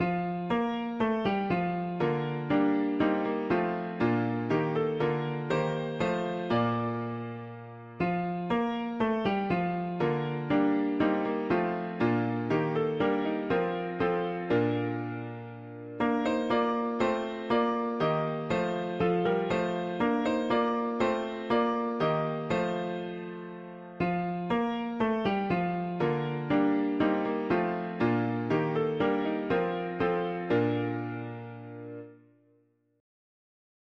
I sing the wisdom that or… english theist 4part chords
Key: B-flat major Meter: CMD